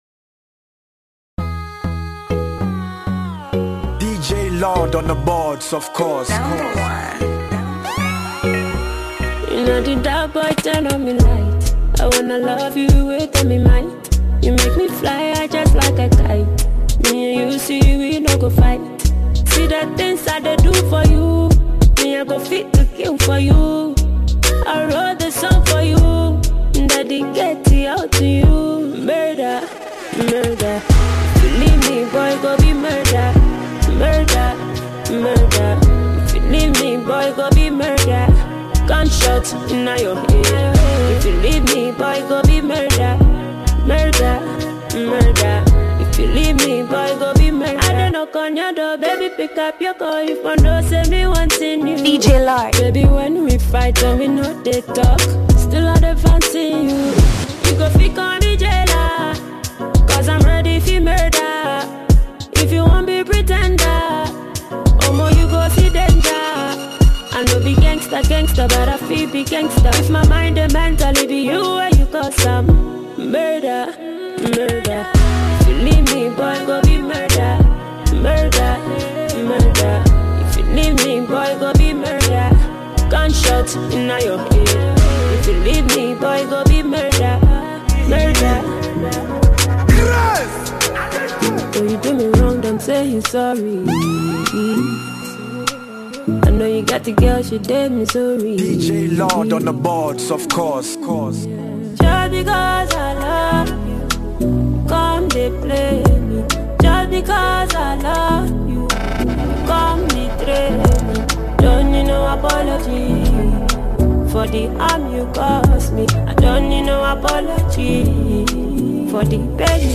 Genre: Mixtape